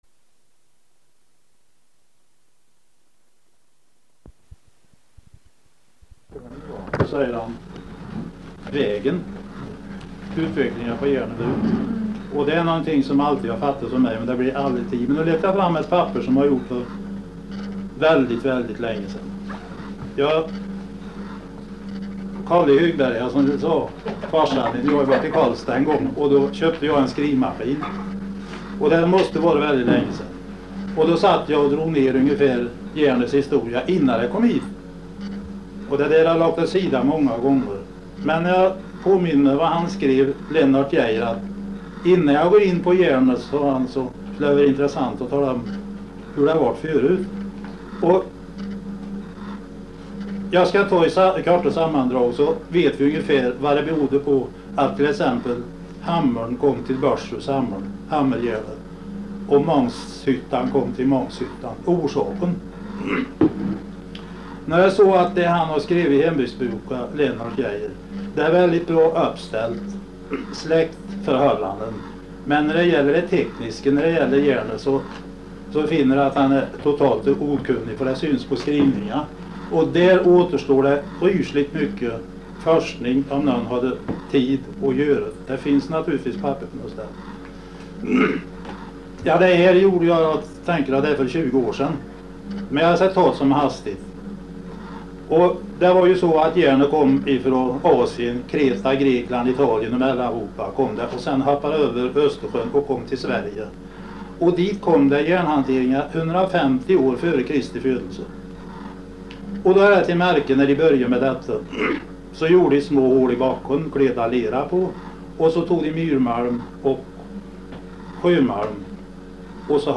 Originalinspelningen på kassettband överförd till mp3-format.